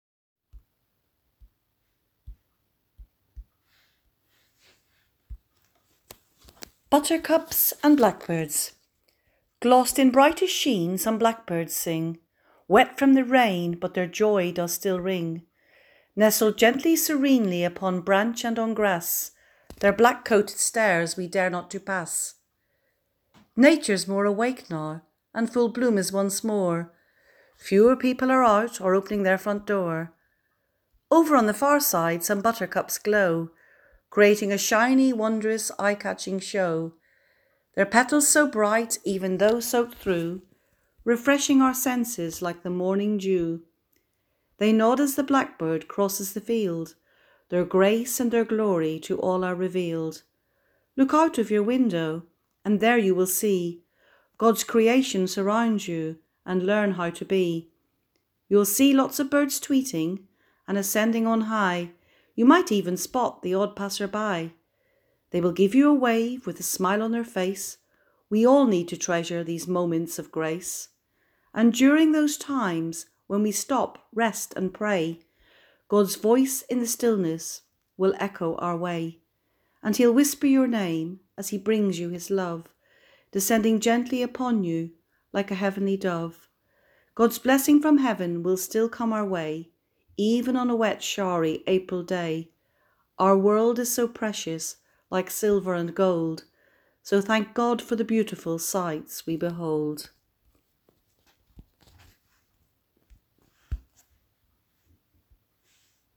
Hymns and Music